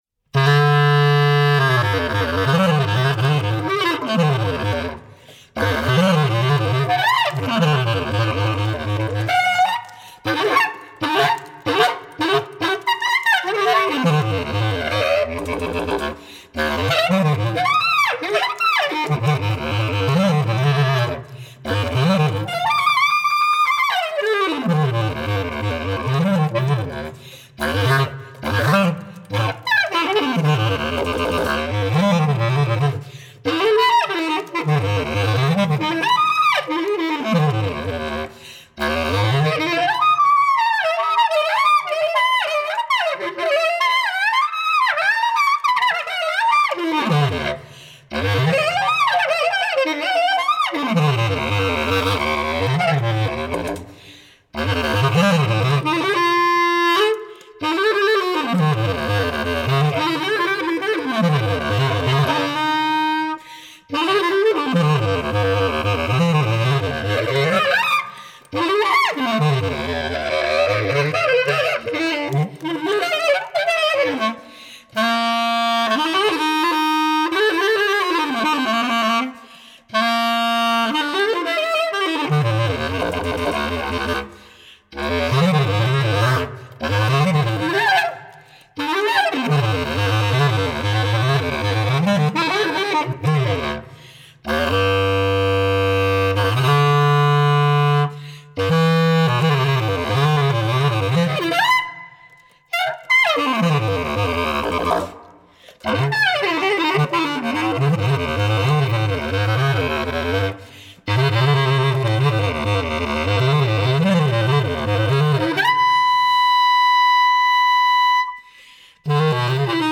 solo bass clarinet